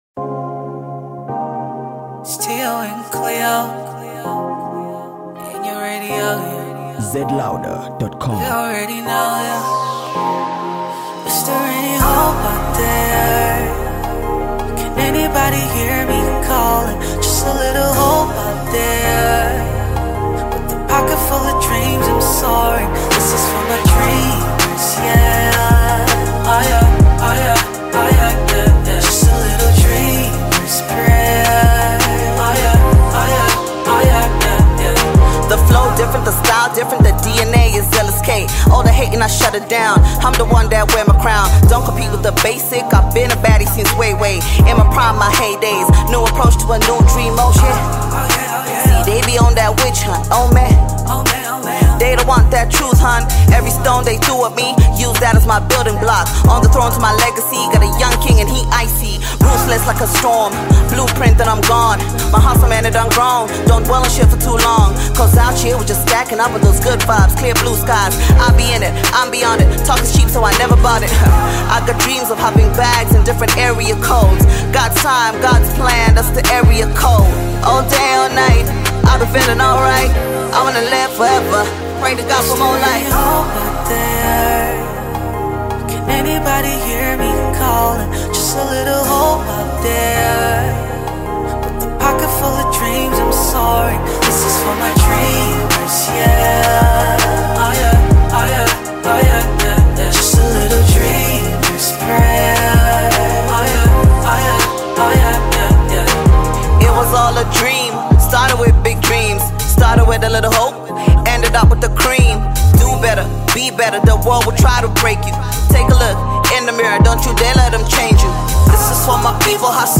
Zambian Female Music Sensation